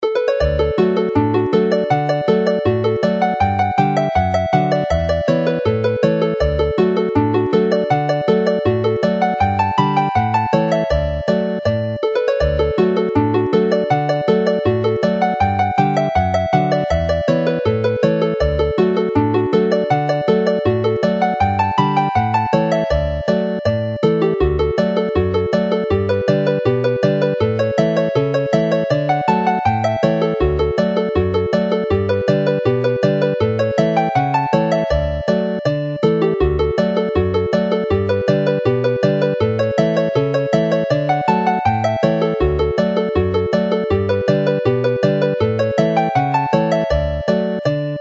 as a reel